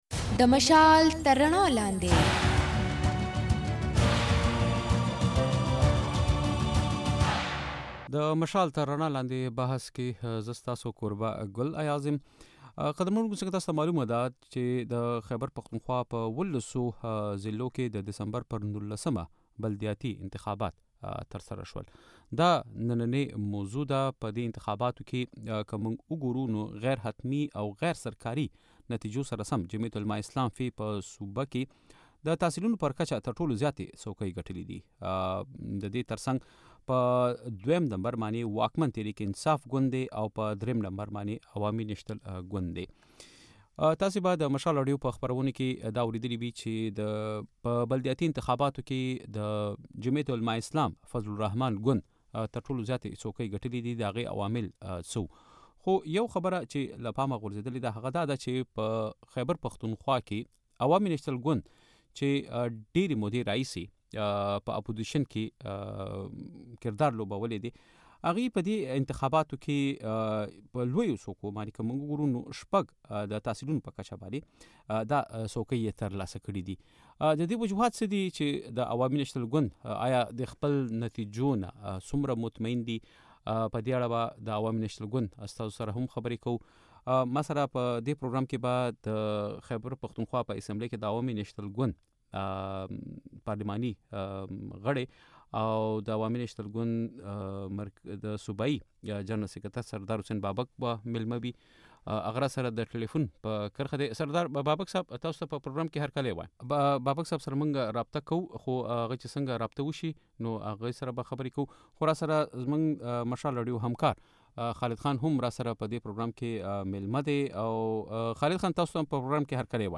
د مشال تر رڼا لاندې بحث کې مو په خيبر پښتونخوا کې د ډېسېمبر پر ۱۹مه د بلدياتي انتخاباتو لومړي پړاو غيرحتمي او غير سرکاري نتيجو موضوع څېړلې ده. له غير سرکاري نتيجو سره سم، جمعيت علمای اسلام (ف) د تحصيلونو پر کچ تر ټولو زياتې څوکۍ ګټلې دي.